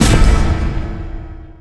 quest_fail.wav